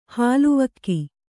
♪ hāluvakki